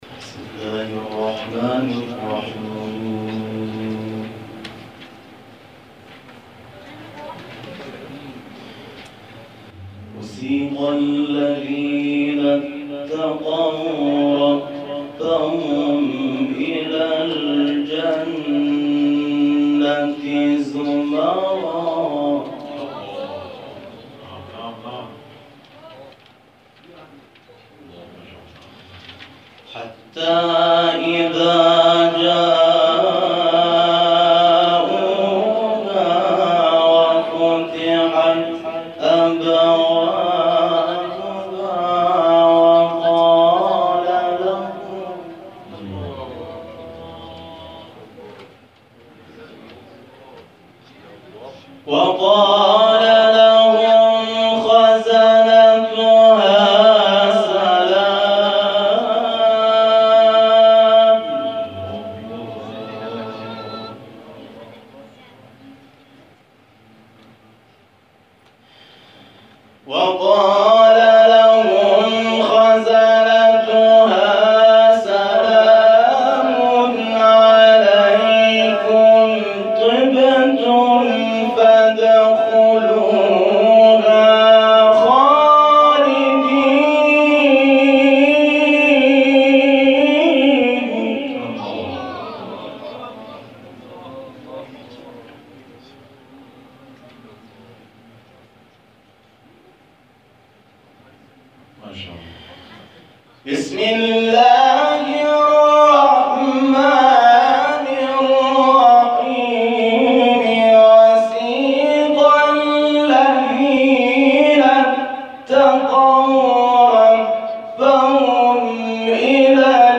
در آستان امامزادگان پنج تن لویزان برگزار شد؛